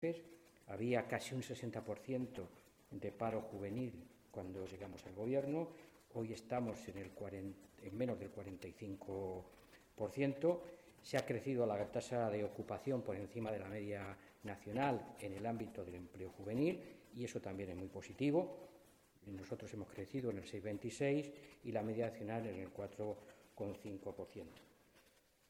Mora, que ha realizado estas declaraciones momentos antes del inicio de la comisión de Economía y Empleo en las Cortes regionales, también quiso poner en valor que este plan ha conseguido obtener mejores resultados que los que han obtenido planes similares en otras comunidades autónomas.
Cortes de audio de la rueda de prensa